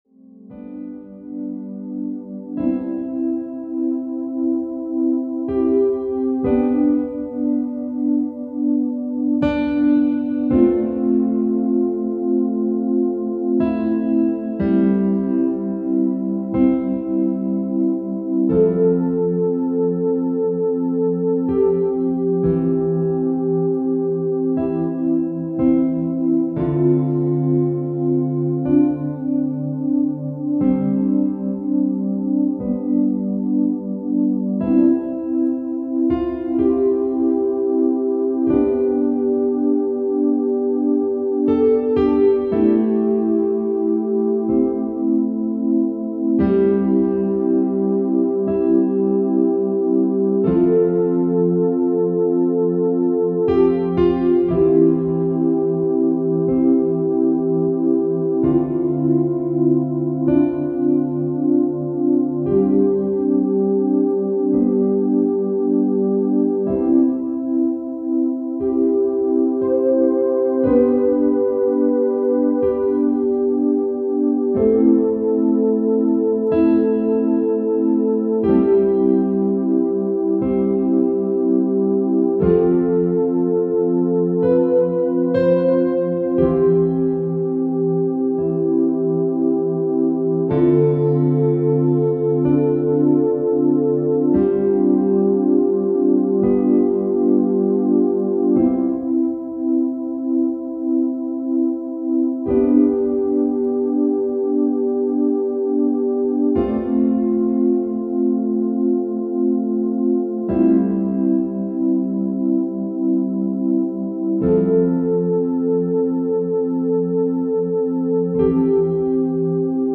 a collection of musical, meditational poetry